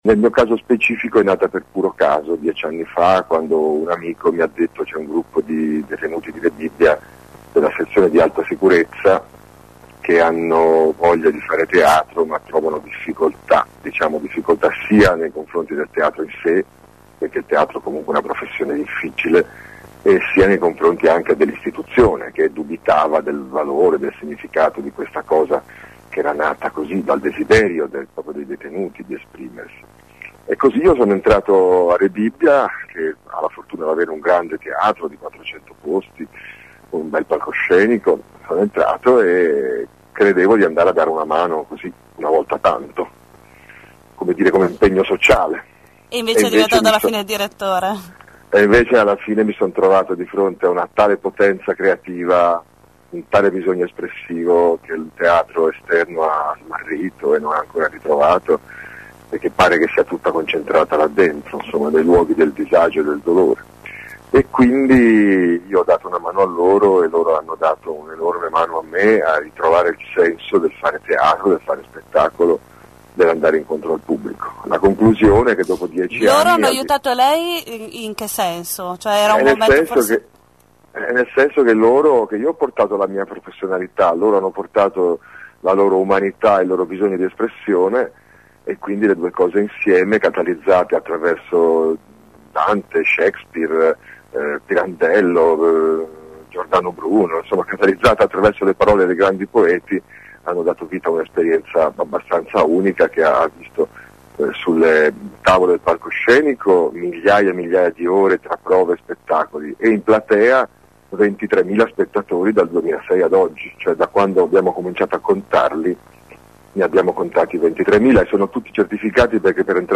Lunedì sera all’Arena Puccini è stato proiettato “Cesare deve morire”, il film dei fratelli Taviani vincitore dell’Orso d’Oro al Festival di Berlino. L’intervista [...]